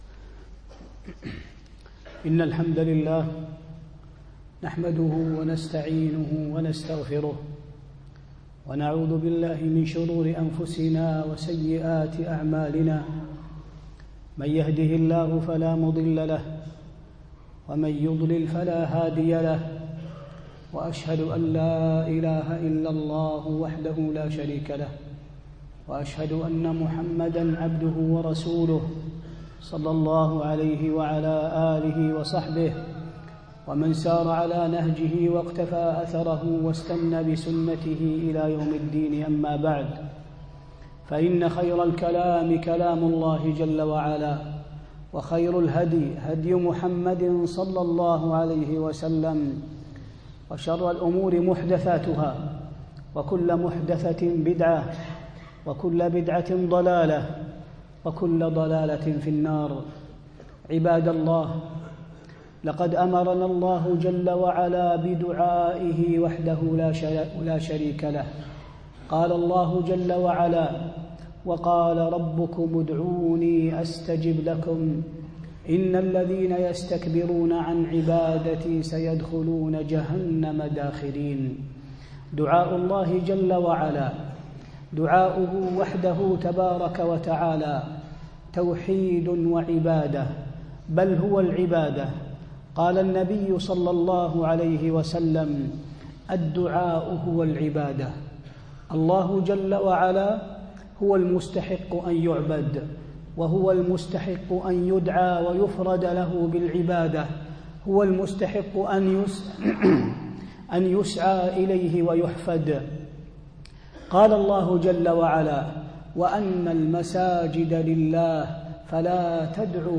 خطبة الدعاء